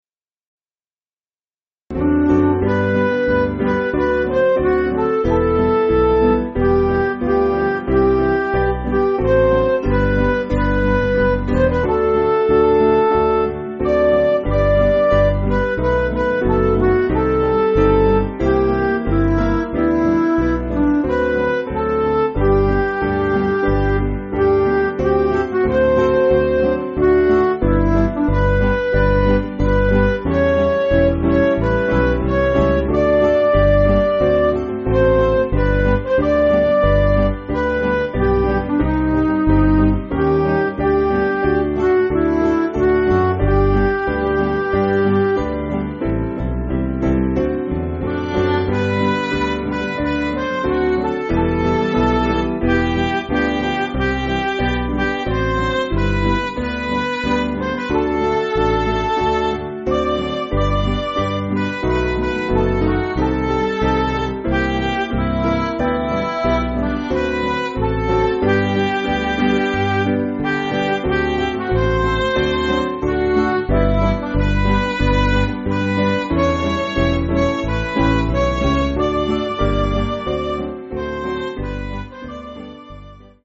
Piano & Instrumental
(CM)   4/G
Midi